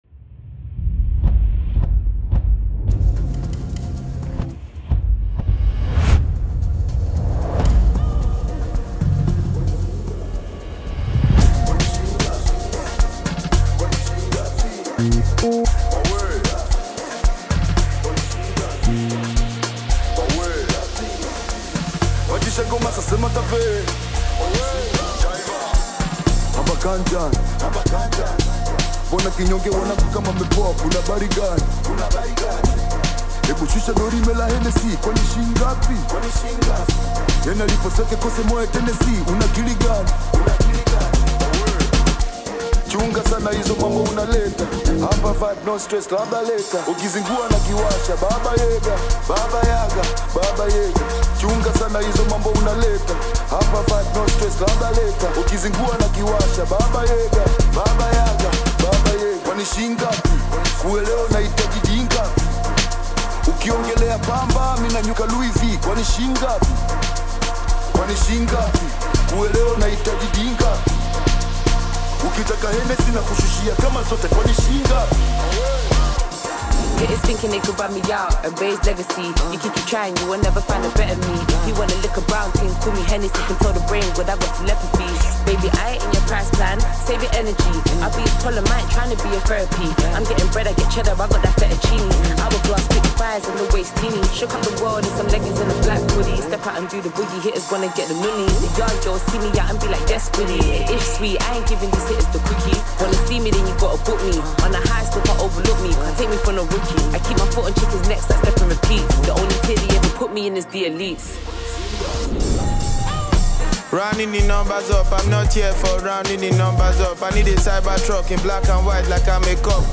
Team up with rappers from different countries
It is a banger for the street